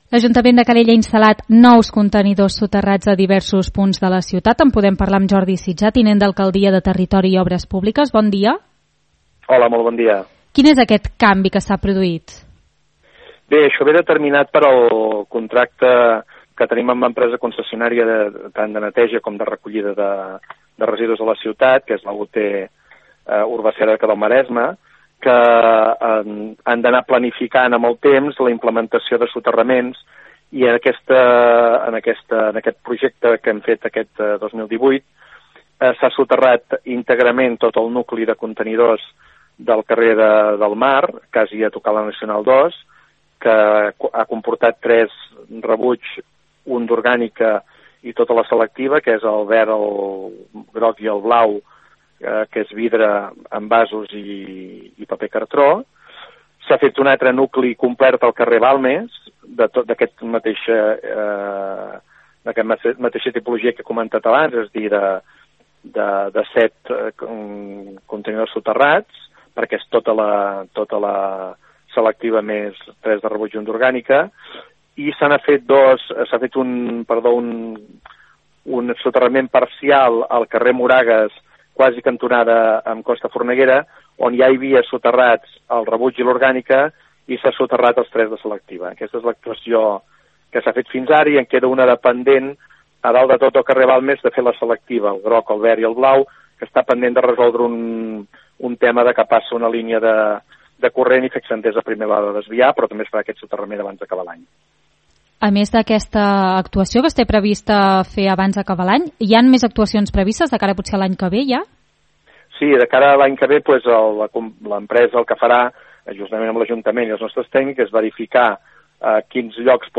A continuació podeu recuperar l’entrevista íntegra al tinent d’Alcaldia de Territori i Obres Públiques, Jordi Sitjà.